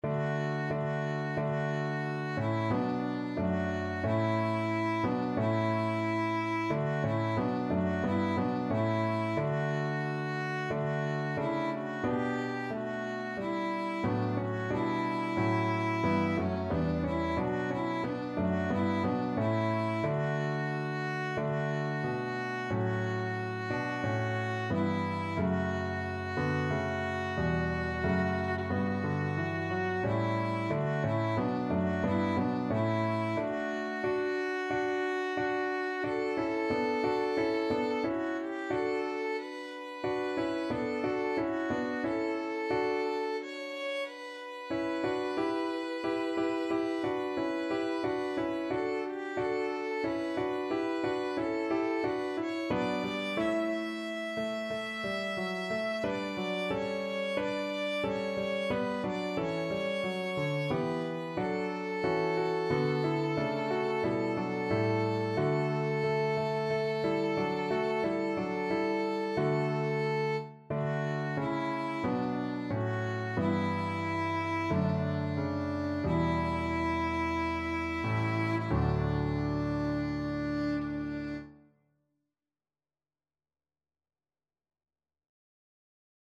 Violin version
4/4 (View more 4/4 Music)
Classical (View more Classical Violin Music)